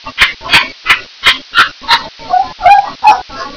I am a Congo African Grey Parrot.
I am can say "up up", "hi", "hello", and "kiss kiss".
I will try to make a better one, but it is much more fun to chew on the microphone.